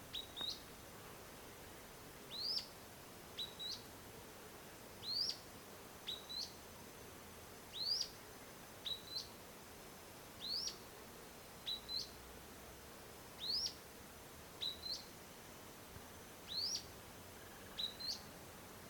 Grey-bellied Spinetail (Synallaxis cinerascens)
Location or protected area: Bio Reserva Karadya
Condition: Wild
Certainty: Recorded vocal